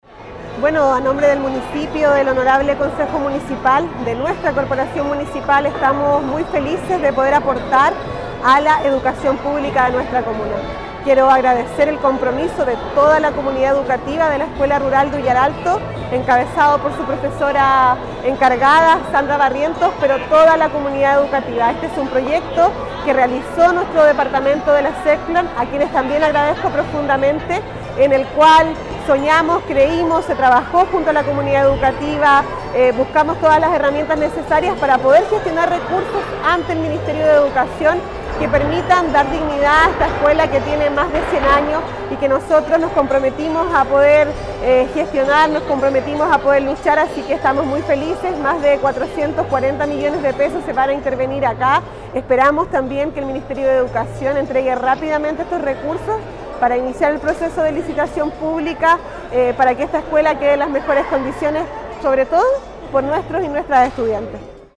Al respecto la alcaldesa Javiera Yáñez, declaró: